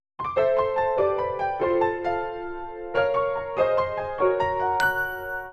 PianoSteps.ogg